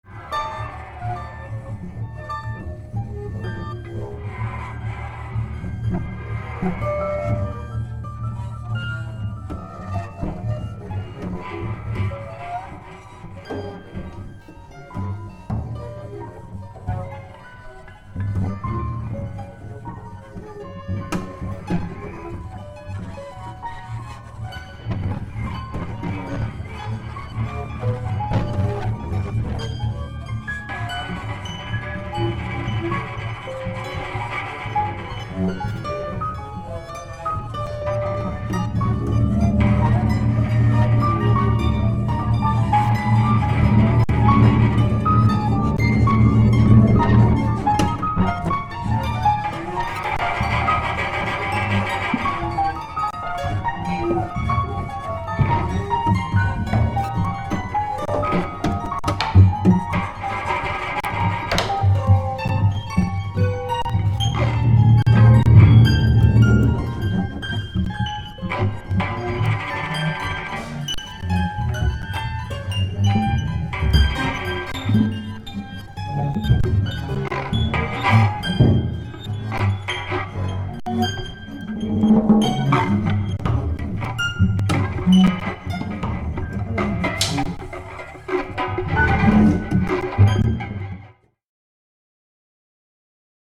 「ガキッゴキッ」とクールな音響が終始弾け飛ぶ非常にカッコイイ内容です。
all tracks spontaneous improvisations by
clavichord
extended piano
electronics
percussion
cello